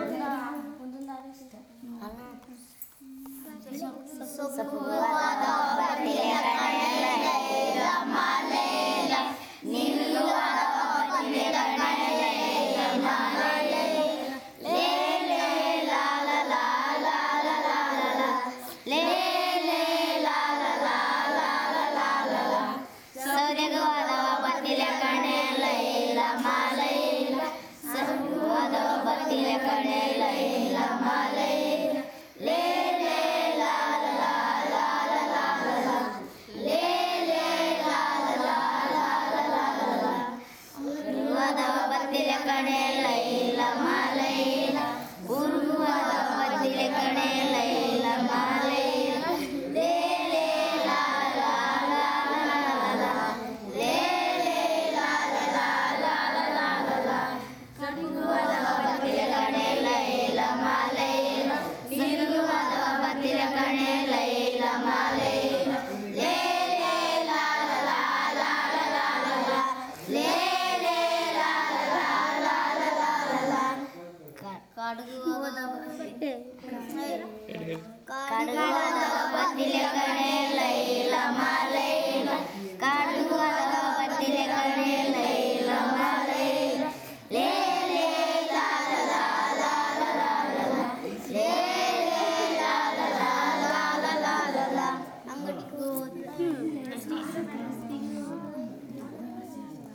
Performance of folk song about forest